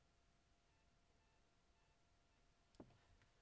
Zoom H2N-noise when recording direct to Audacity thru USB audio interface - macOS - Audacity Forum
Here’s a wav I made today but the phone was about 4 ft away, so I can’t be sure it’s not the issue.